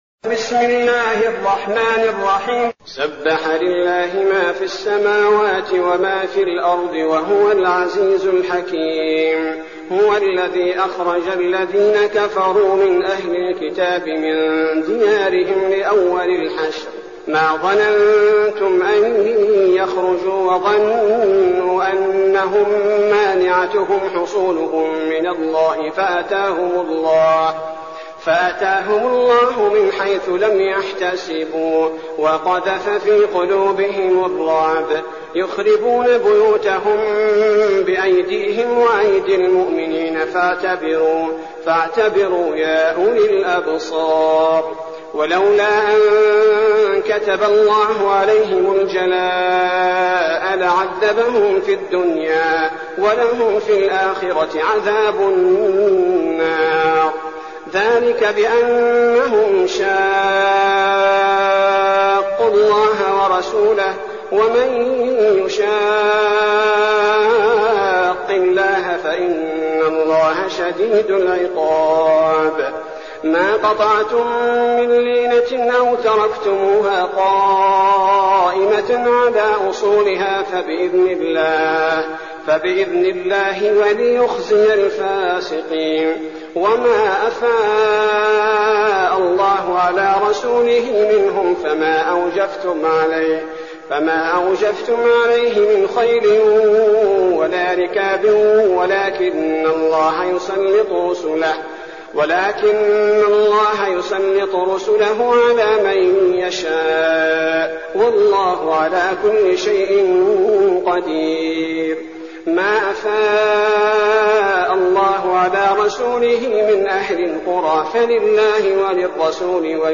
المكان: المسجد النبوي الشيخ: فضيلة الشيخ عبدالباري الثبيتي فضيلة الشيخ عبدالباري الثبيتي الحشر